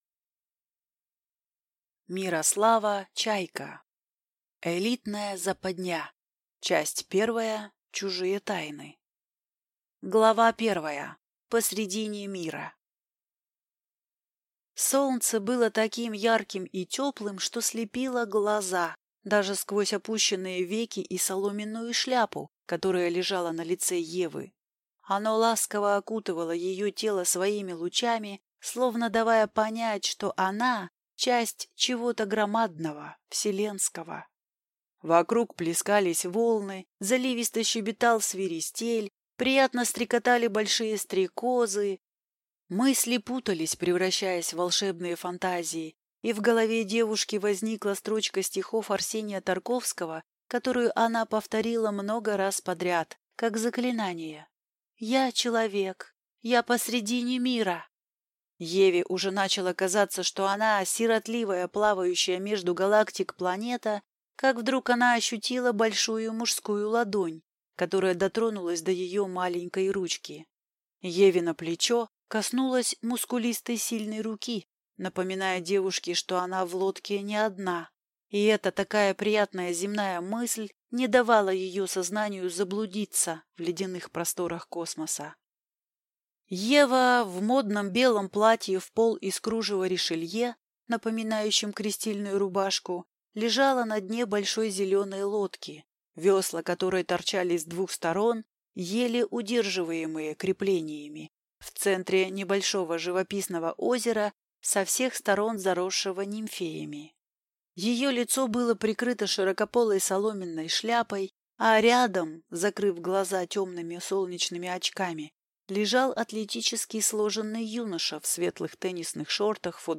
Аудиокнига Элитная западня. Часть первая. Чужие тайны | Библиотека аудиокниг